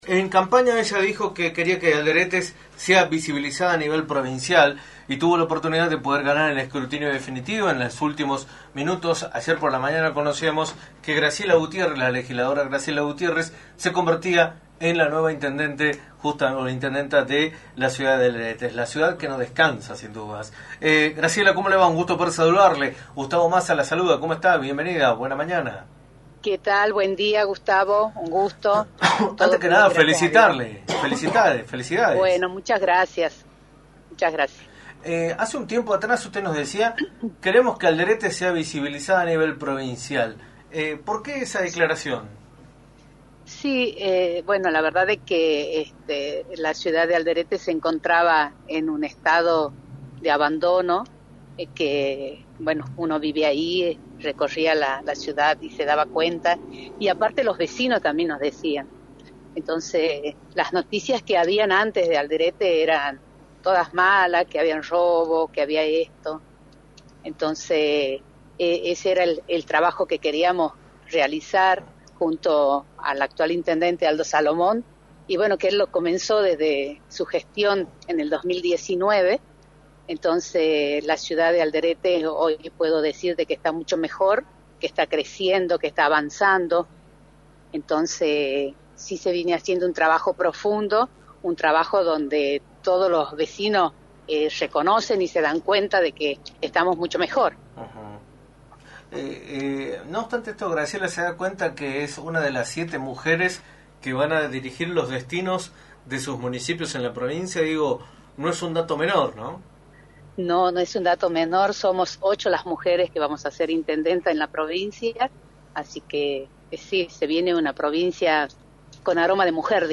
Graciela Gutiérrez, Intendente electa de Alderetes, analizó en Radio del Plata Tucumán, por la 93.9, el escenario político provincial, luego de que el escrutinio definitivo confirmara su triunfo en las elecciones realizadas el 11 de junio.
“Las noticias que había antes de Alderetes eran malas y junto a Aldo Salomón queríamos cambiar eso, hoy puedo decir que la ciudad está mucho mejor, creciendo y avanzando, estamos mucho mejor” señaló Graciela Gutiérrez, en entrevista para “La Mañana del Plata”, por la 93.9.
GRACIELA-GUTIERREZ-DEL-PLATA.mp3